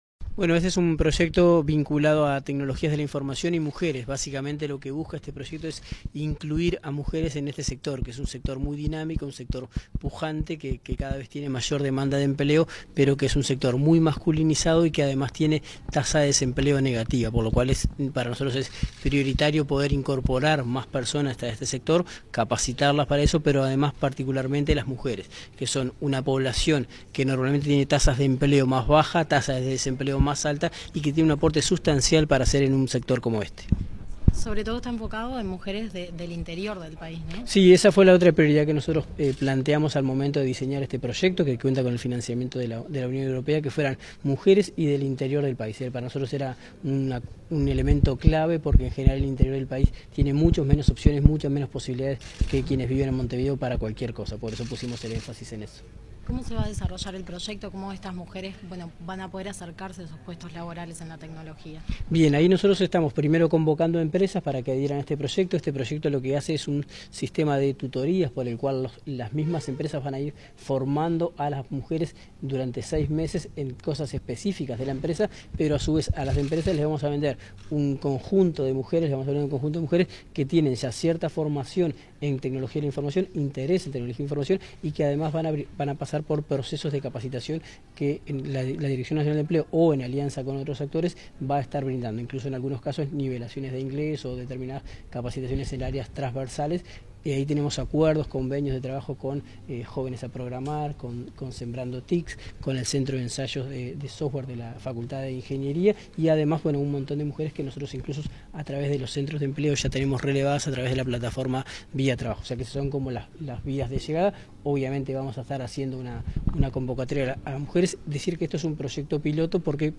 Declaraciones a la prensa del director nacional de Empleo, Daniel Pérez
El Ministerio de Trabajo y Seguridad Social (MTSS) presentó, este 16 de agosto, el proyecto Mujeres en el Sector Tecnologías de la Información, para capacitar durante seis meses a unas 200 personas mayores de 18 años de Colonia, Maldonado, Paysandú, Río Negro, Rivera, Salto, San José y Soriano. Tras participar el evento, el director nacional de Empleo efectuó declaraciones a la prensa.